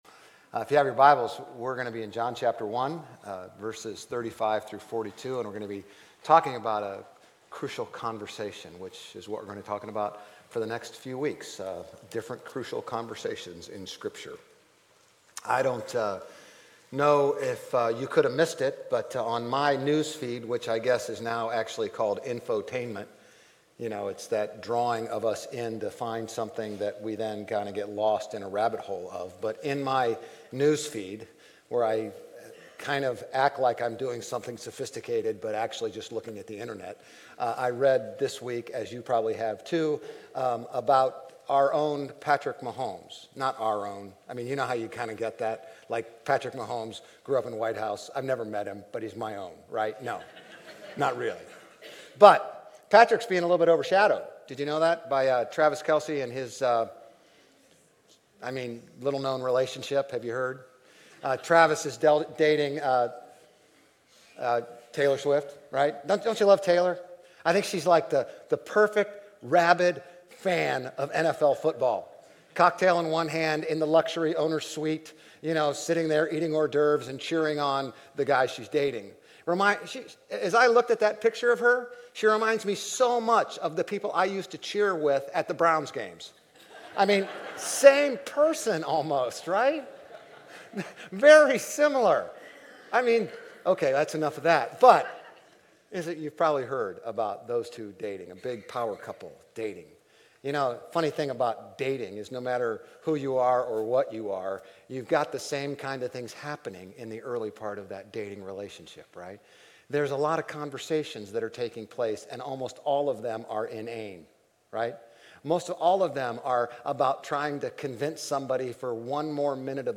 GCC-OJ-October-8-Sermon.mp3